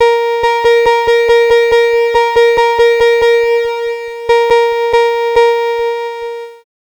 Cheese Lix Synth 140-A#.wav